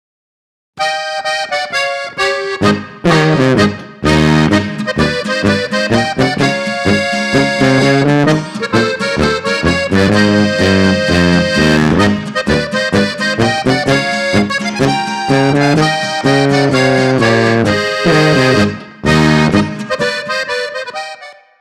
vll ist jetzt das bariton etwas laut, aber bei dieser art von musik muss das bariton ja treiben.